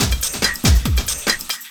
04 LOOP01 -R.wav